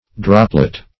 droplet \drop"let\, n.